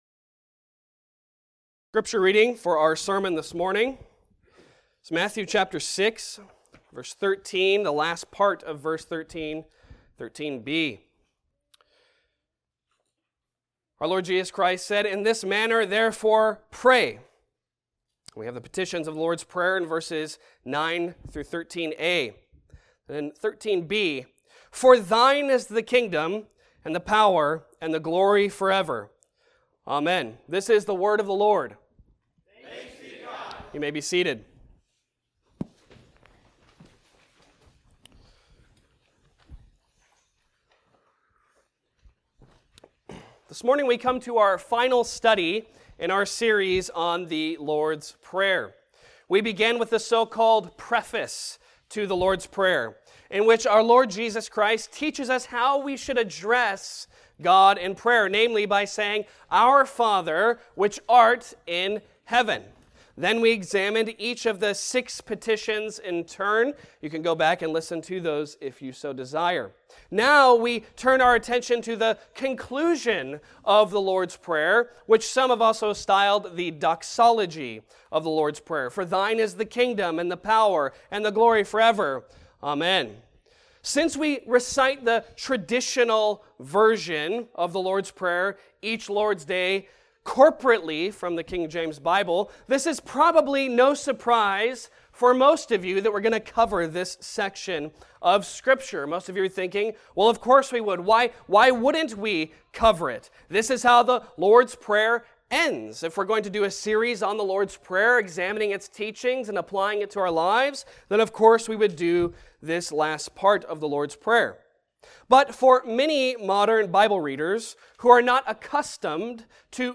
Passage: Matthew 6:13b Service Type: Sunday Sermon